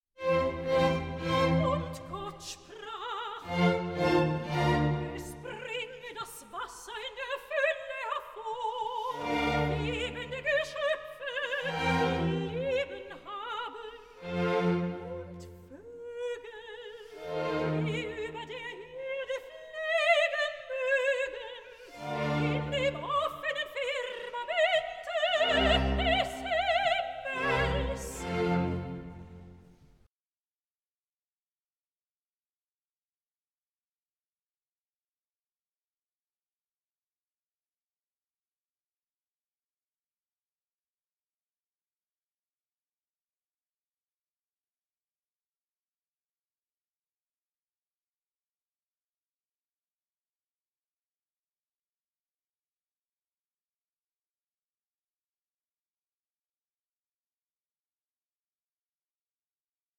Classical transparency and Romantic drive